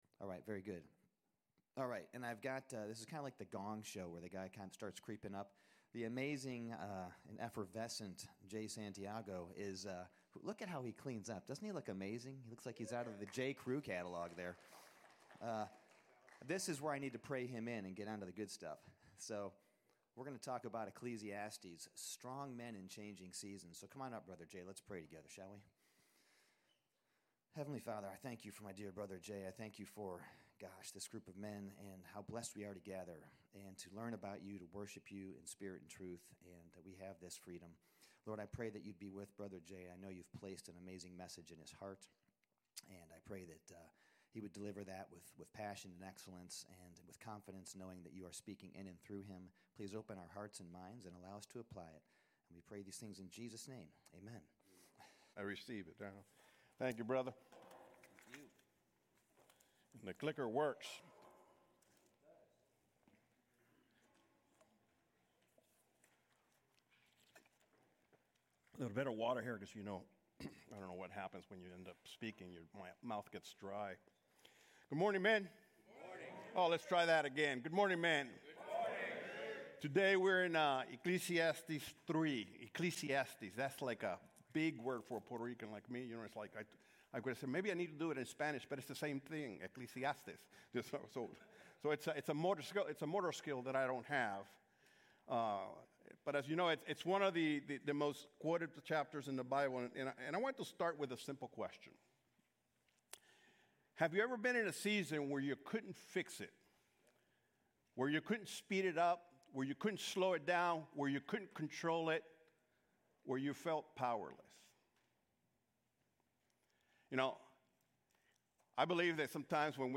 2026 Burke Community Church Lesson